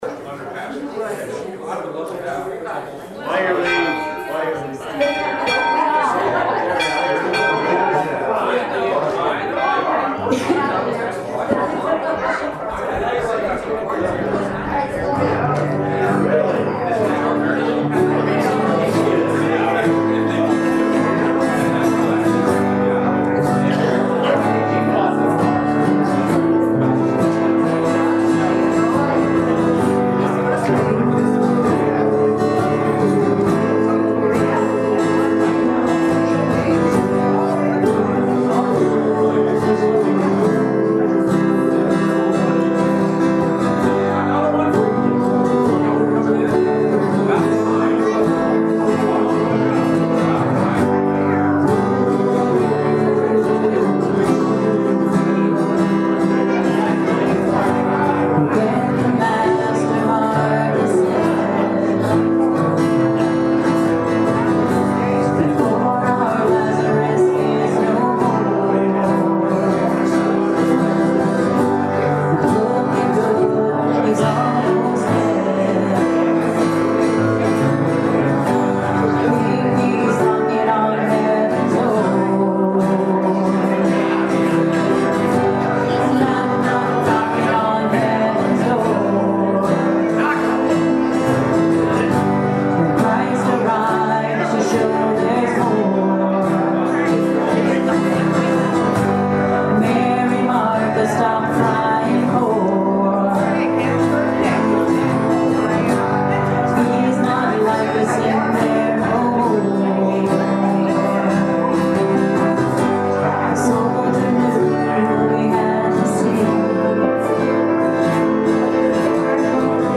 April 30th, 2017 Service Podcast
Prelude: Knocking on Heaven’s Door